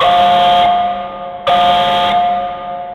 containerAlarm.ogg